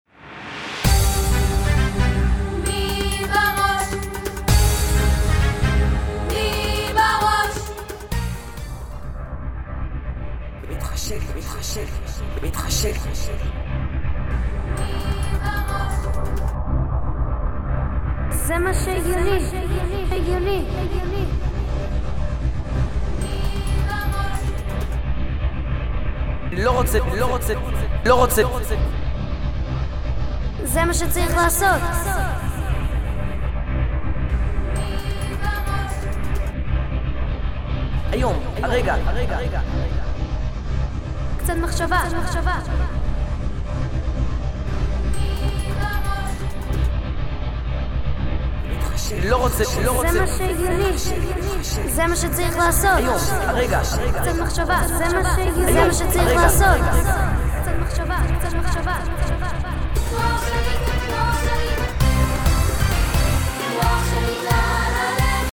שיר